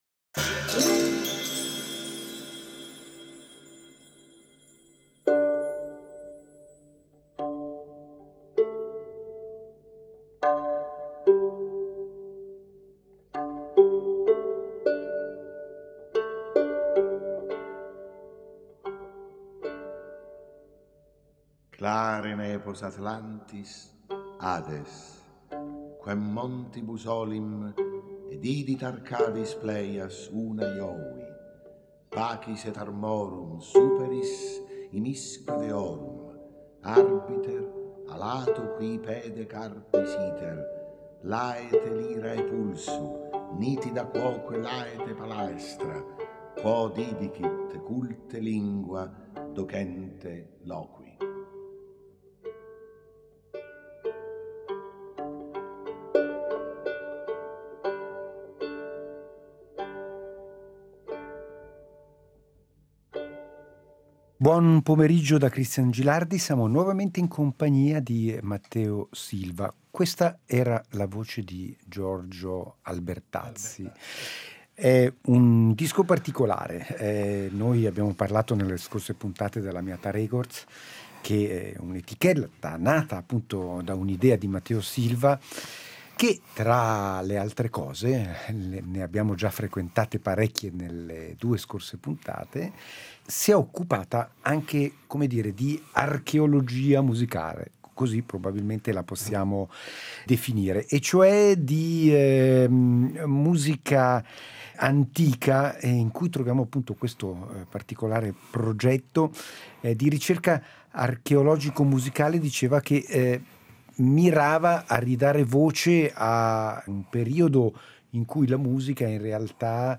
lunga intervista